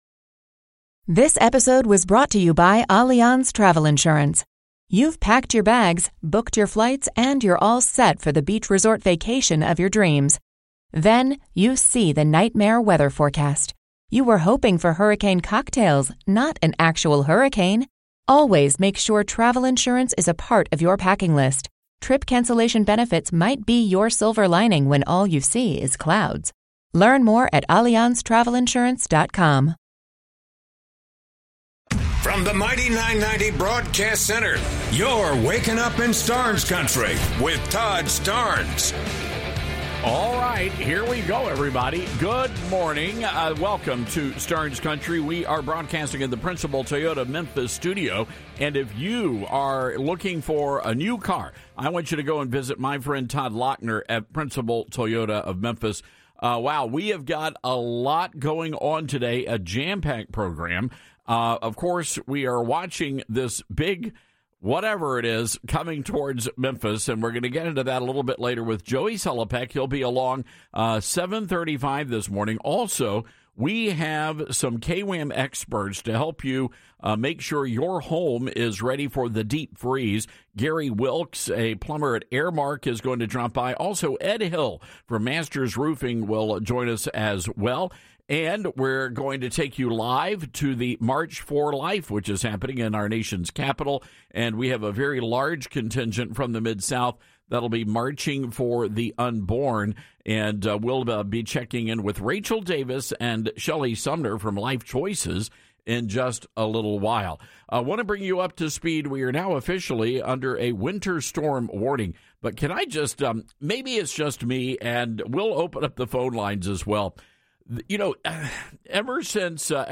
We will have full coverage of the storm along with guidance from our KWAM experts on how to protect your home from the deep freeze. Plus, a live report from the March for Life in Washington DC and an interview with Mississippi Gov. Tate Reeves.